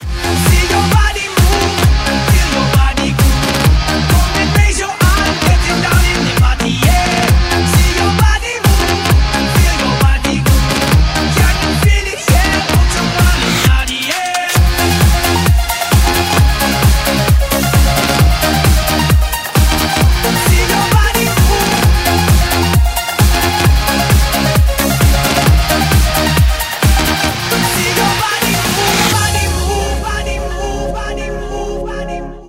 • Качество: 192, Stereo
Клубный рингтон